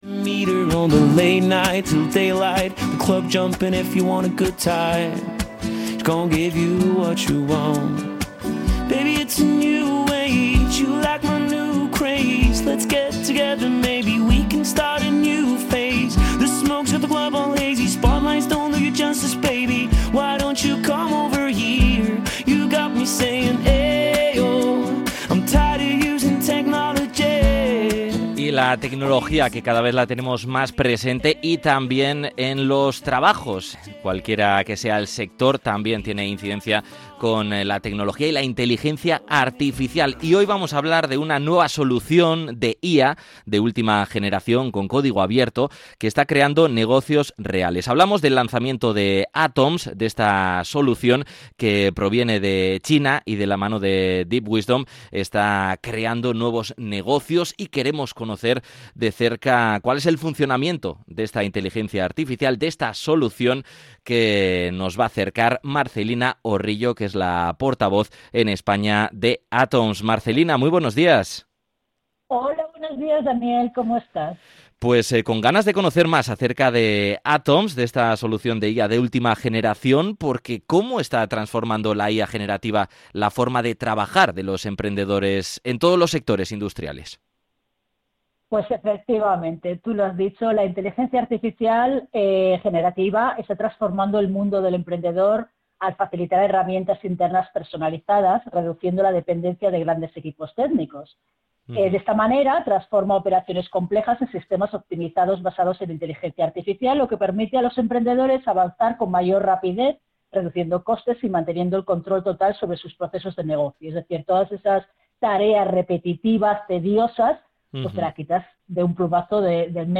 Podcast Tecnología